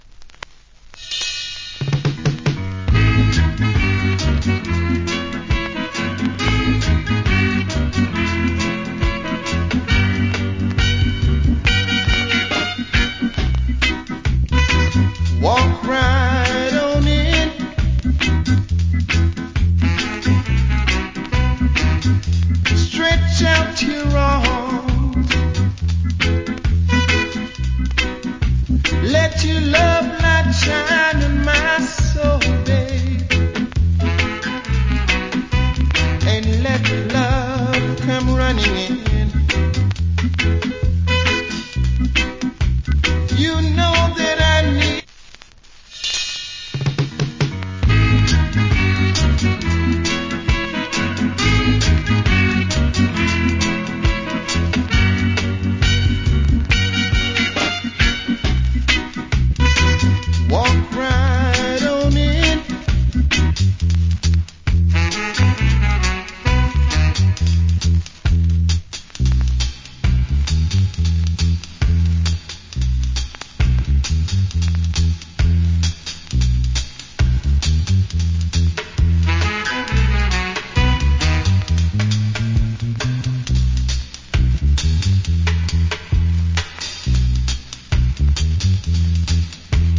Reggae Vocal